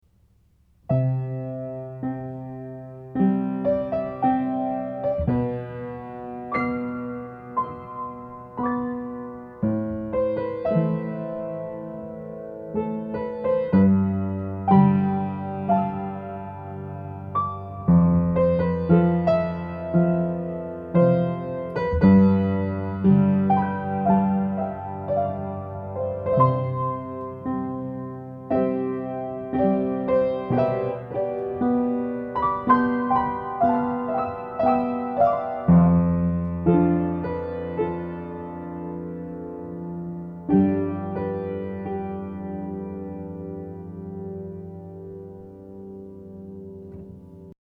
What kind of scene do these melodies suggest?
amor.mp3